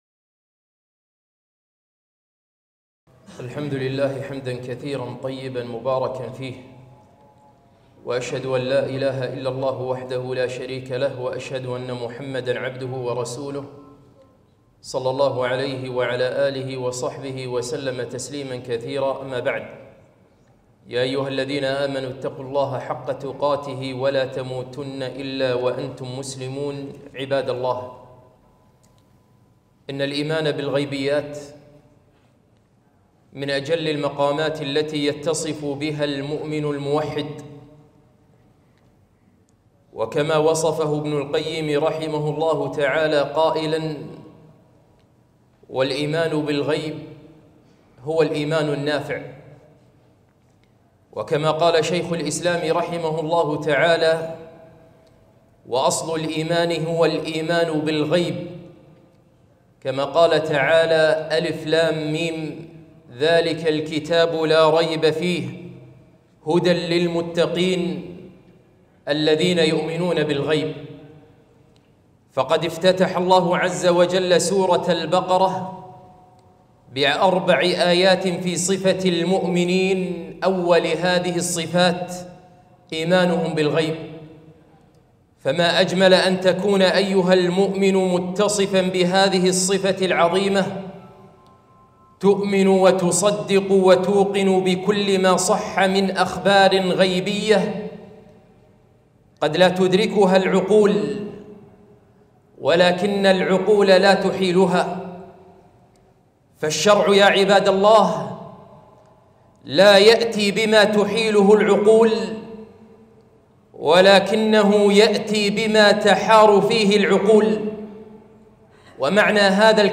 خطبة - هل تؤمن بالغيبيات ؟